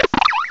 cry_not_tranquill.aif